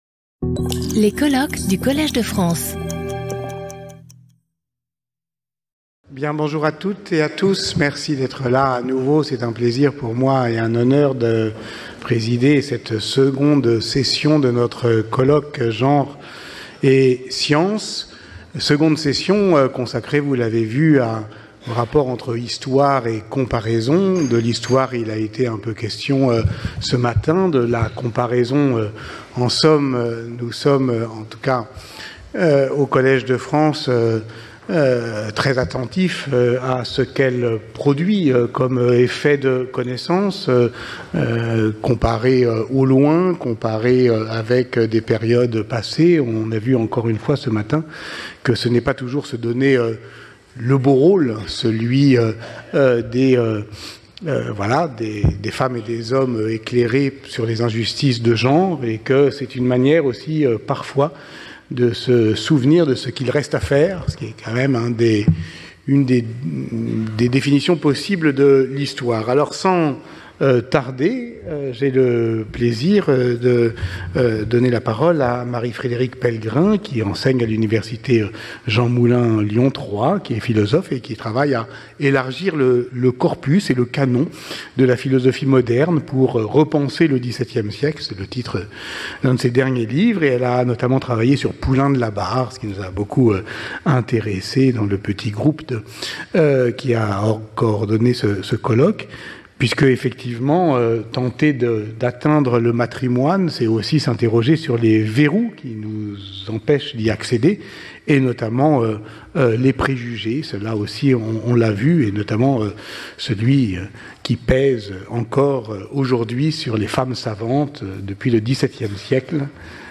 Sauter le player vidéo Youtube Écouter l'audio Télécharger l'audio Lecture audio Séance animée par Patrick Boucheron. Chaque communication de 30 minutes est suivie de 10 minutes de discussion.